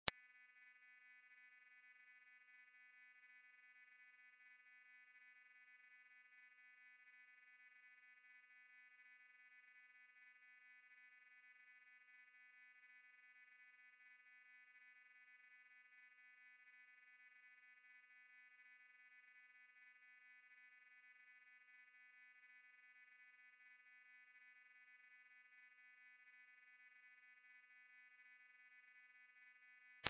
» Ультразвук Размер: 238 кб